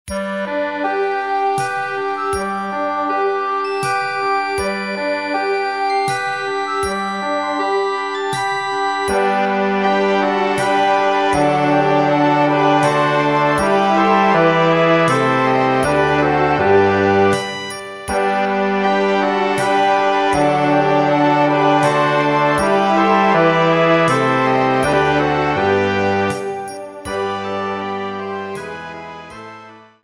Kolędy